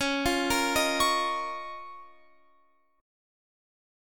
Listen to Db6add9 strummed